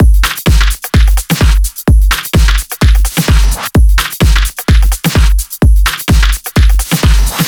VFH1 128BPM Southern Kit 1.wav